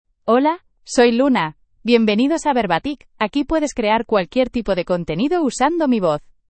Luna — Female Spanish (Spain) AI Voice | TTS, Voice Cloning & Video | Verbatik AI
LunaFemale Spanish AI voice
Luna is a female AI voice for Spanish (Spain).
Voice sample
Listen to Luna's female Spanish voice.
Luna delivers clear pronunciation with authentic Spain Spanish intonation, making your content sound professionally produced.